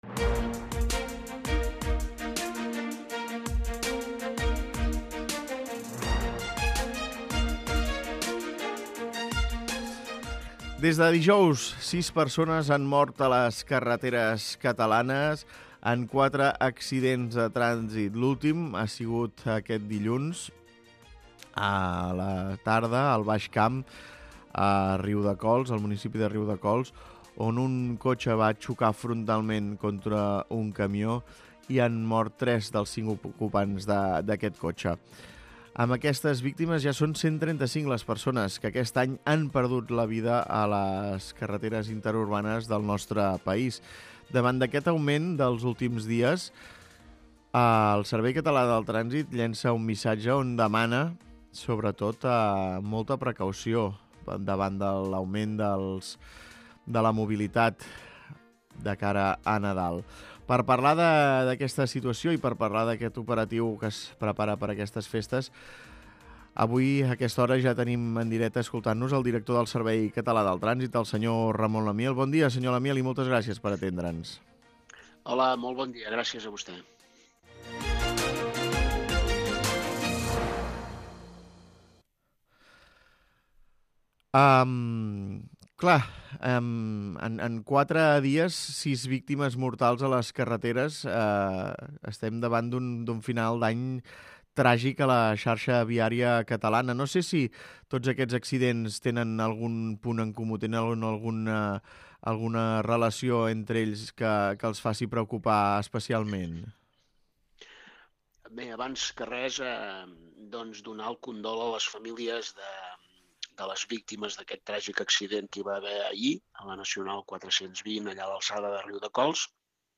Escolta l'entrevista a Ramon Lamiel, director del Servei Català de Trànsit